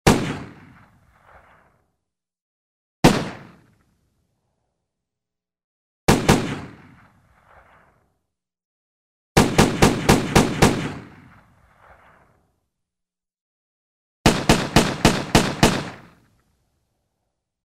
Звуки револьвера
Звуки выстрелов из револьвера второй вариант